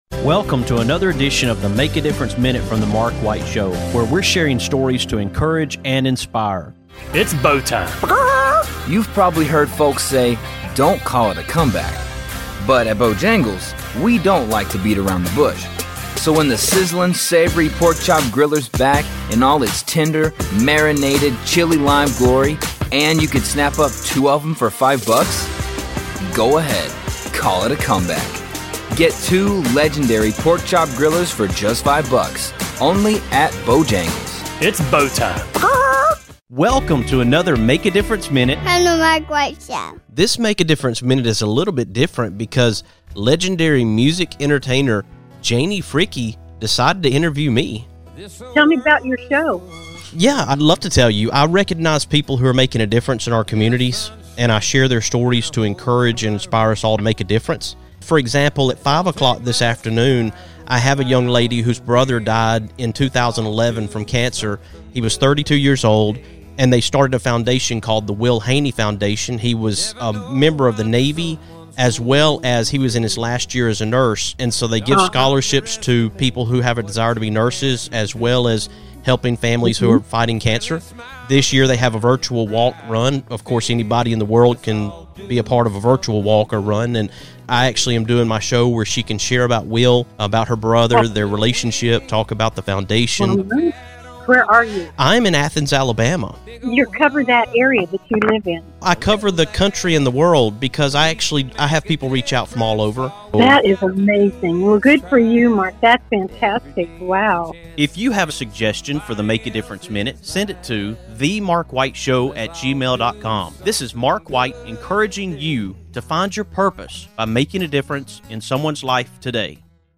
On this MADM, Janie Fricke turns the tables and interviews me.